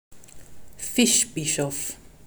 pronunciation)